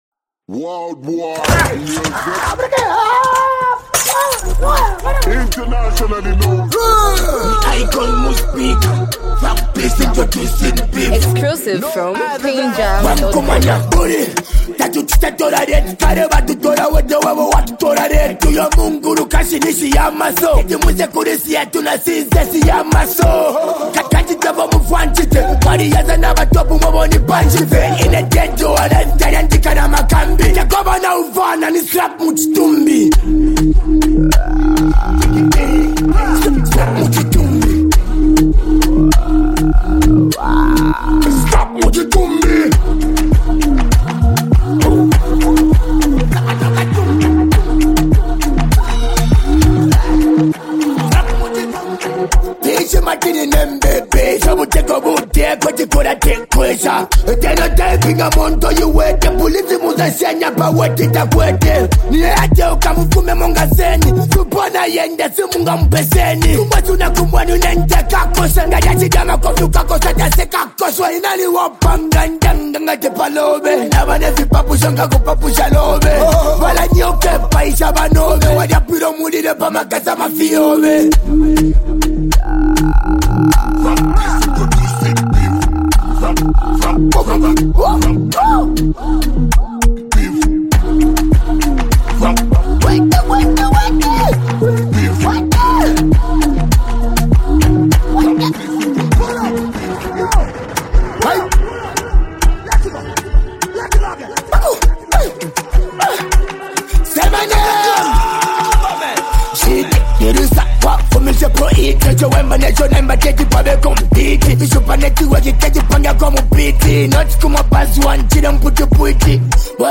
hype, street vibes, and a powerful hook with unique rap flow
diss track